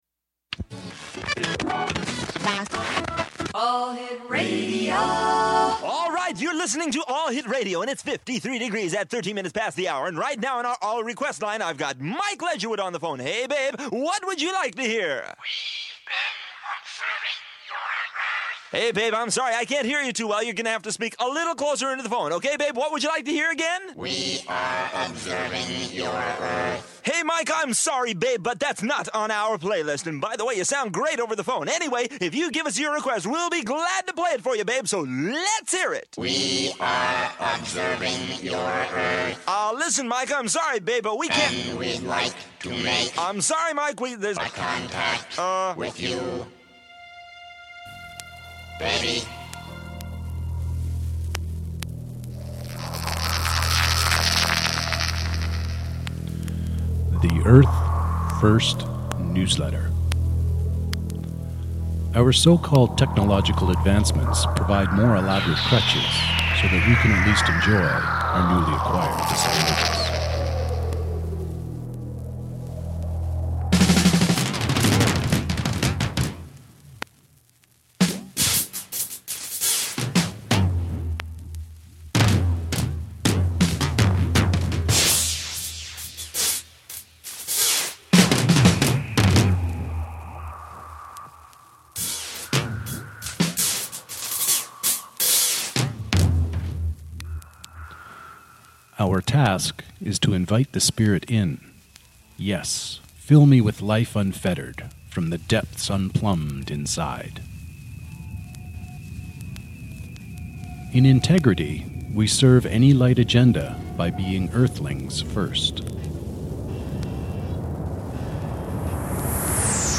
(Author Narration with musical accompaniment: Calling Occupants excerpt Carpenters / Children of the Sun excerpt Billy Thorpe / Cartman / Prophecy SoulFood)